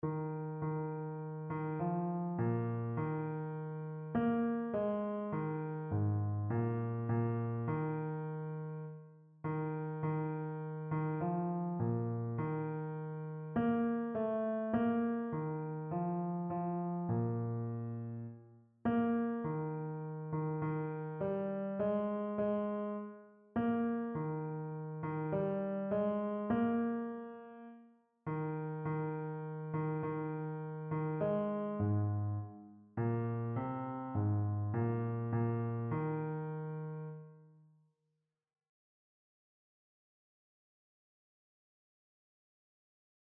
Einzelstimmen (Unisono)
• Bass [MP3] 676 KB